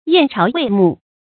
燕巢卫幕 yàn cháo wèi mù
燕巢卫幕发音